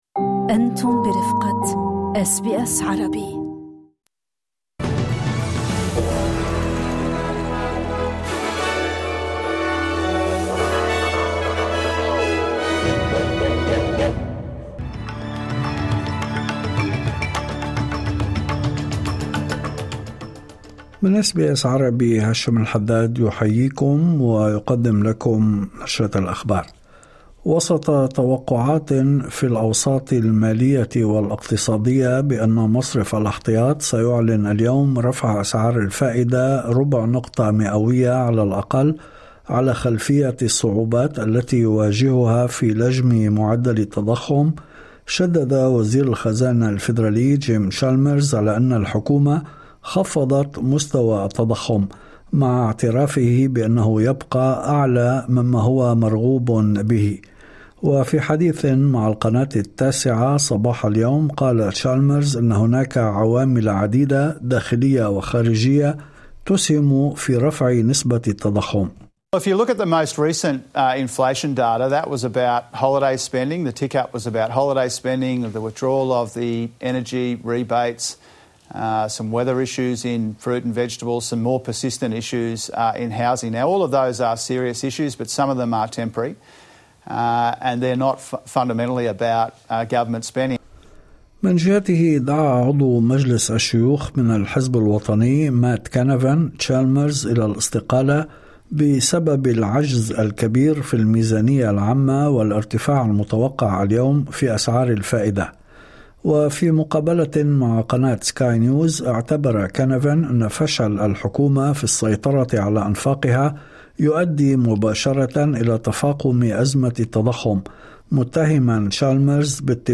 نشرة أخبار الظهيرة 03/02/2026